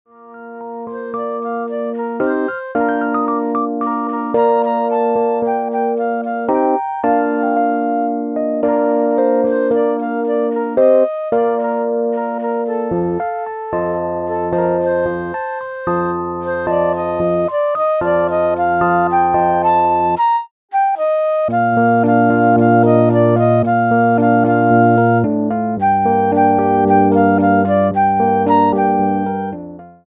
for Flute & Keyboard
A super solo for the Festive Season.